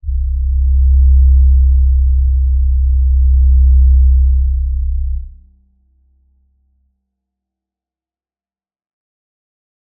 G_Crystal-C2-f.wav